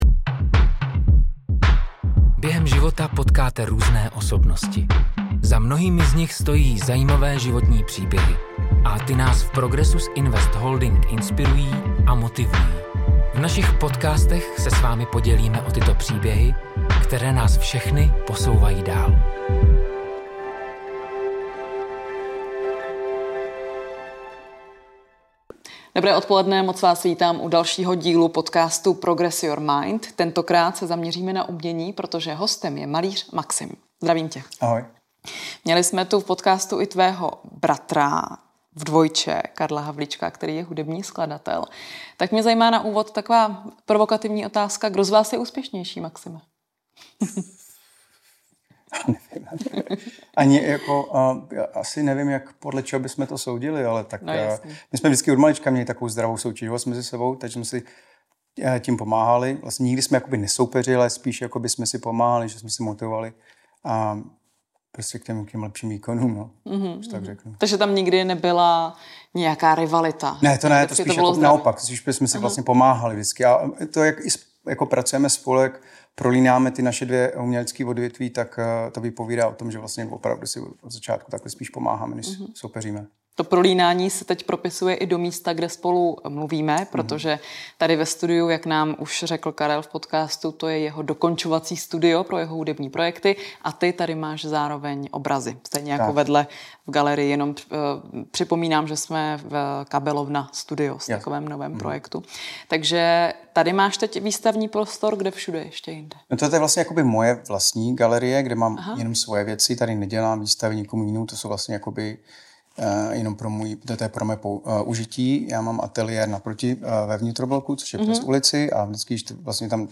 Během našeho setkání hovořil o své tvorbě, experimentování, jak dlouho vzniká jedno dílo, ale také proč jsou to vždy velká plátna. Další díl podcastu přináší rozhovor s umělcem